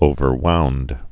(ōvər-wound)